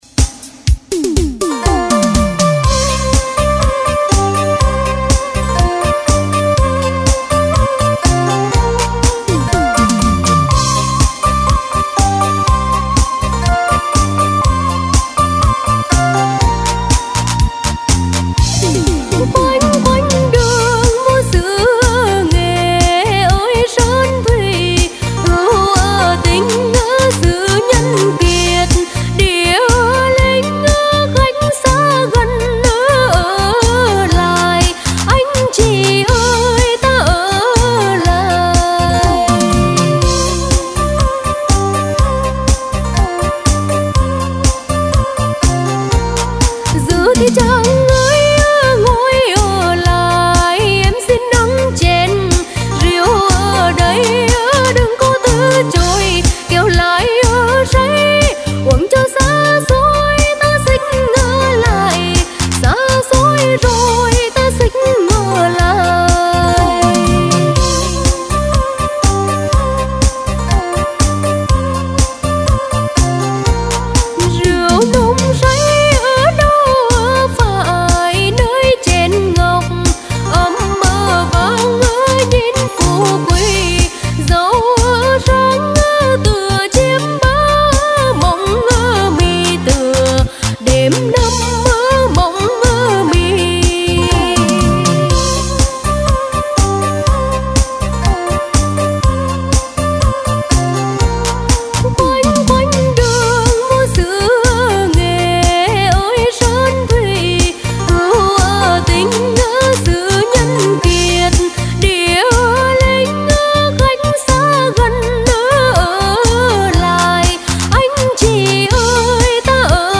thuộc thể loại Dân ca Xứ Nghệ.